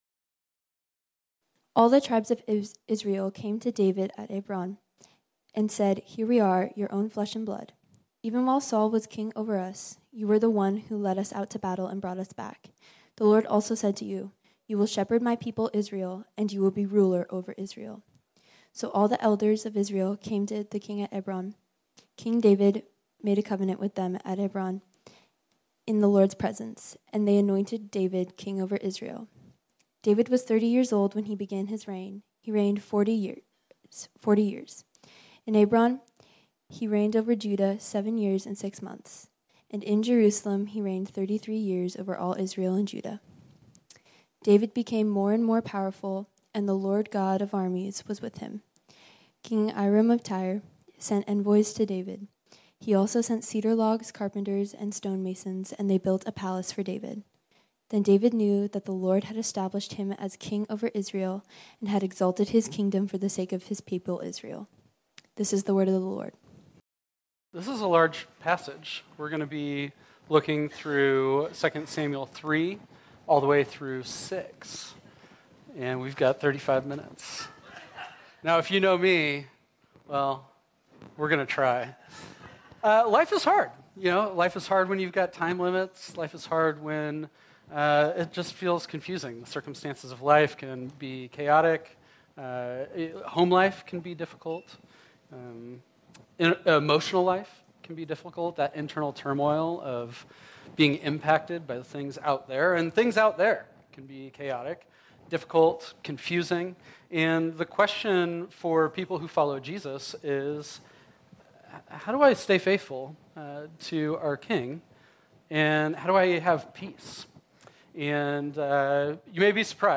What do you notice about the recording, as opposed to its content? This sermon was originally preached on Sunday, July 9, 2023.